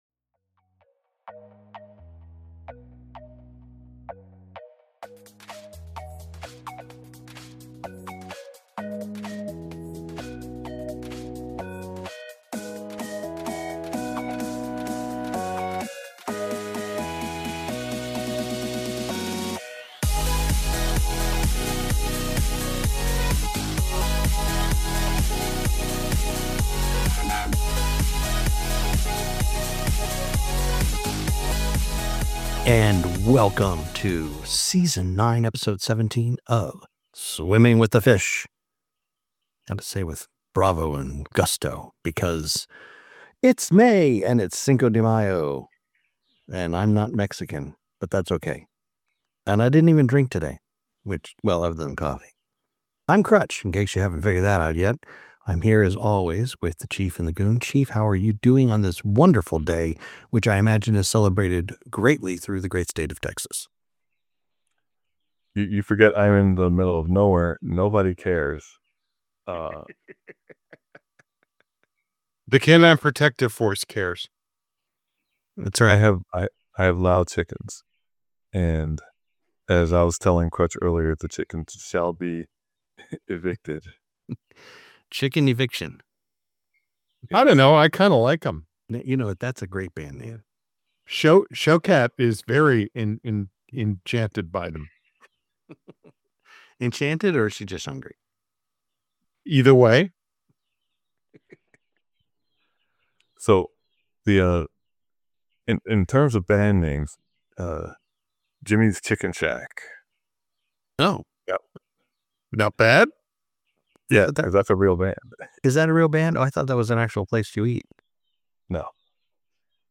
Three guys who like to get together weekly and discuss current events, politics, conspiracy theories and stuff that makes us laugh